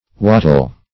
Wattle \Wat"tle\, v. t. [imp. & p. p. Wattled; p. pr. & vb. n.